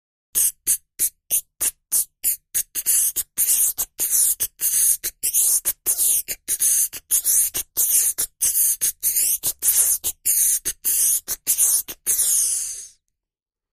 Human Beat Box, Hi-Hats Count (Beat Time)